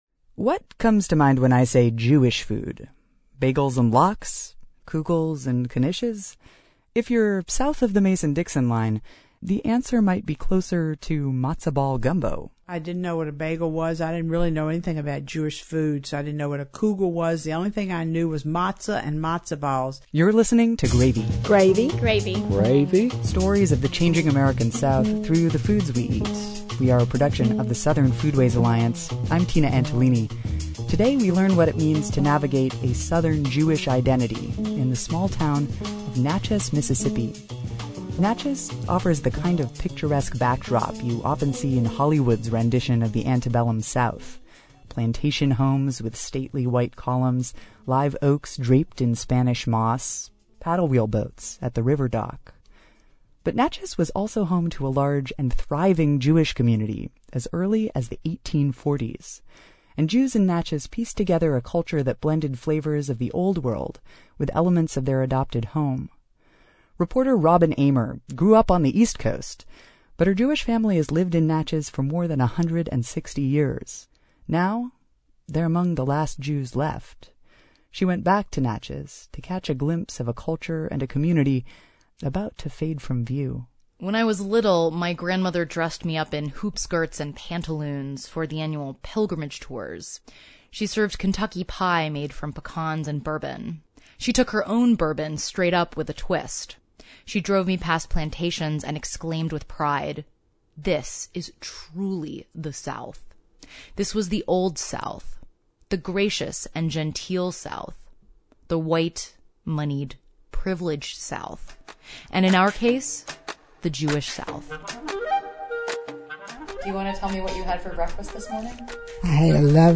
Gravy is an award-winning podcast that uses food as a vehicle to tell longform audio stories about the South.